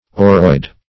Oroide \O"roide\, n. [F. or gold (L. aurum) + Gr. e'i^dos form.]